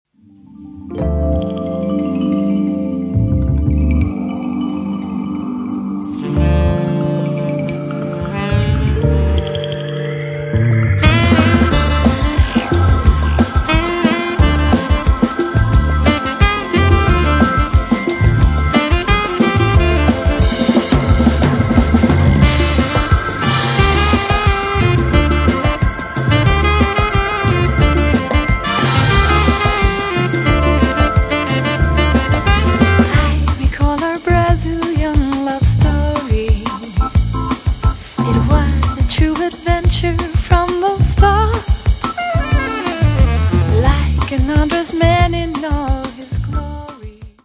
ist ein zeitloser Clubsound